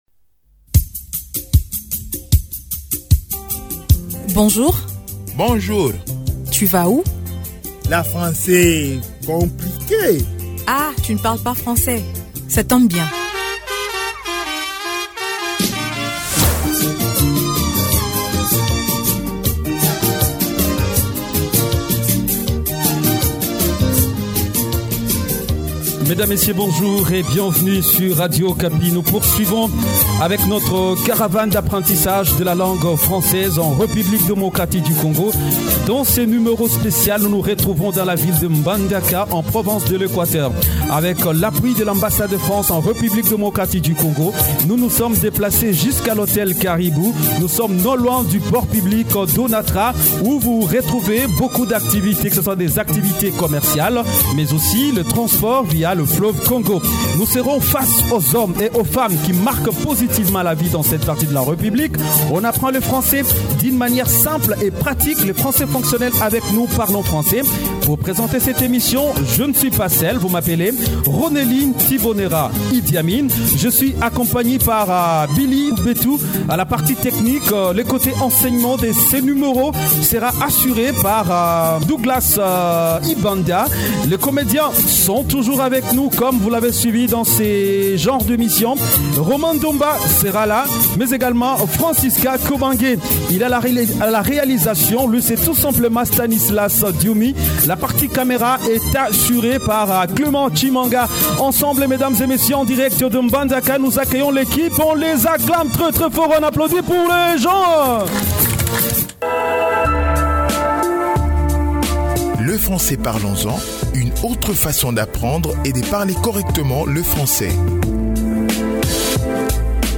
Cette émission a été tournée depuis le marché Lomata, dans la ville de Mbandaka, situé au bord du fleuve Congo, dans la province de l'Equateur.